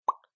bubble.mp3